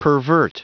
Prononciation du mot pervert en anglais (fichier audio)
Prononciation du mot : pervert